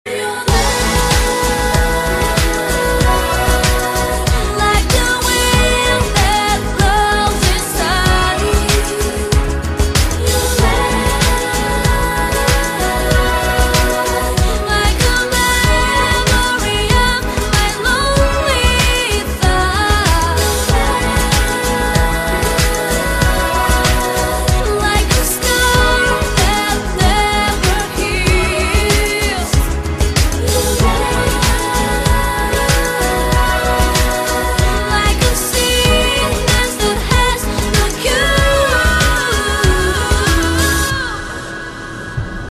M4R铃声, MP3铃声, 欧美歌曲 54 首发日期：2018-05-13 11:02 星期日